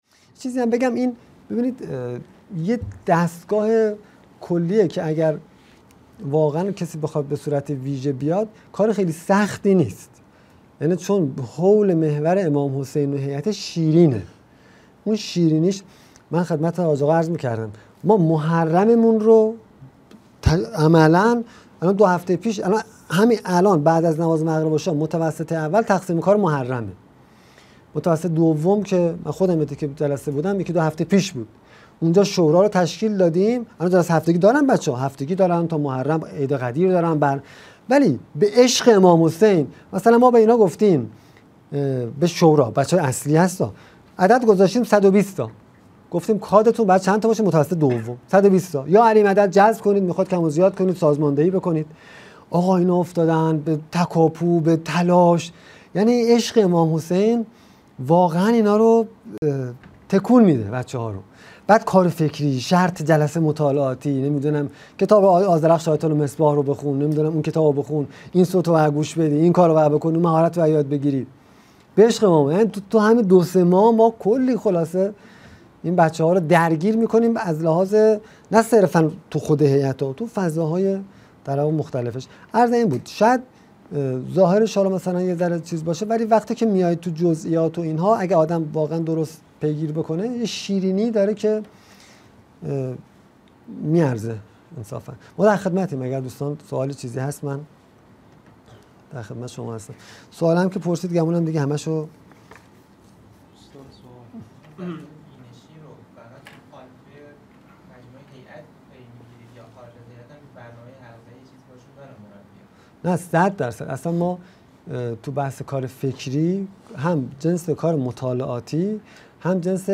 مدرسه عالی هیأت | گزیده هجدهم از سومین سلسله نشست‌ های هیأت و نوجوانان
قم - اردبیهشت ماه 1402